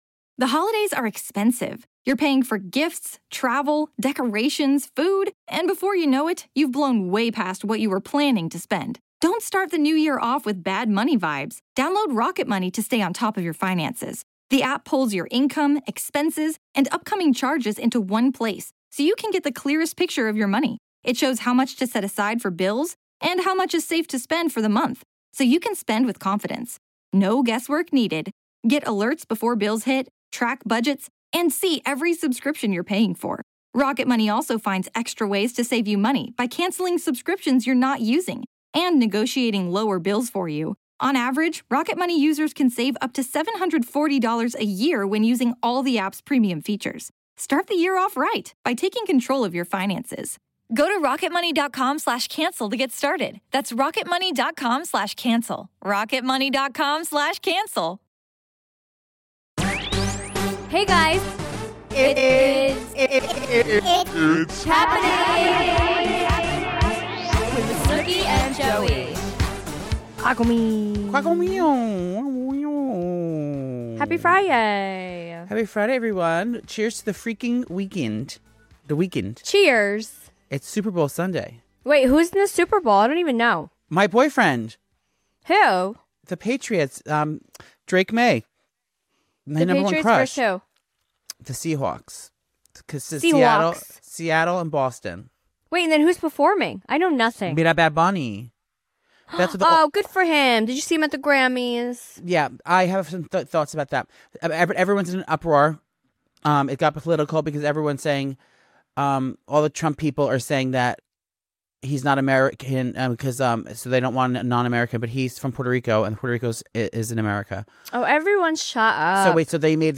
The episode wraps up with listener voicemails.